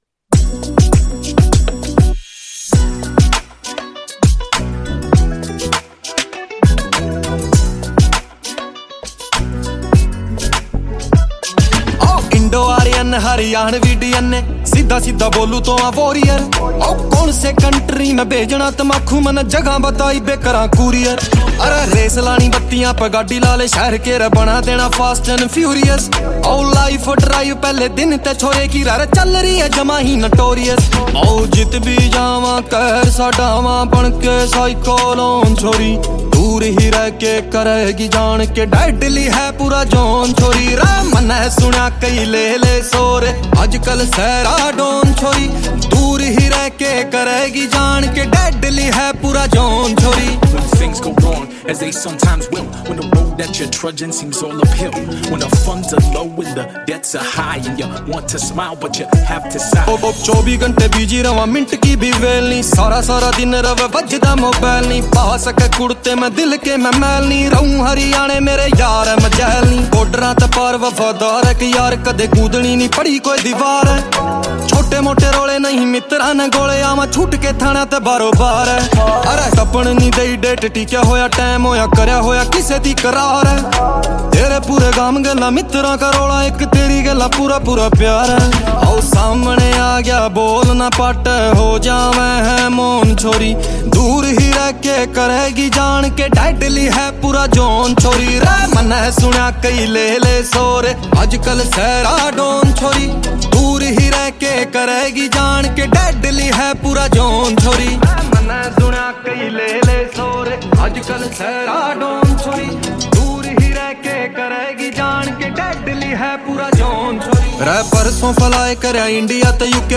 Releted Files Of Latest Haryanvi song